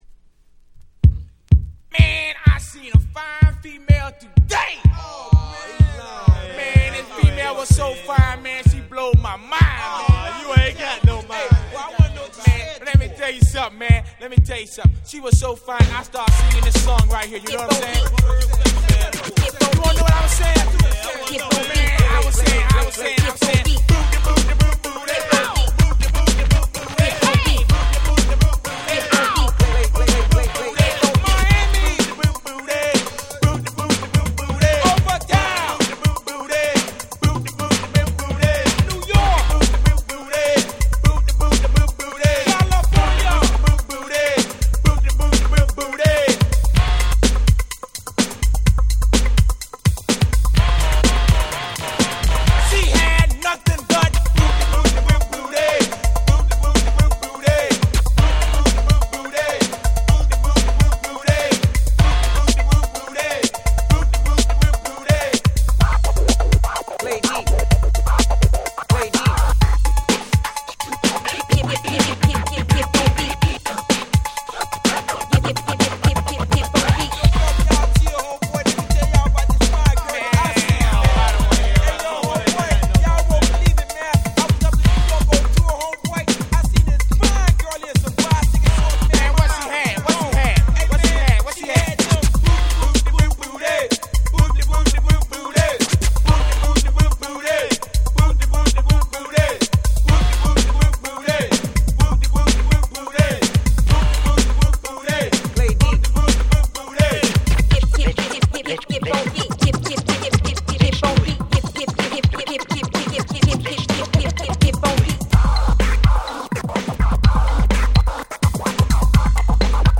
88' Old School / Miami Bass / Electro Super Classics !!
説明不要のパーティーヒップホップクラシックスです！！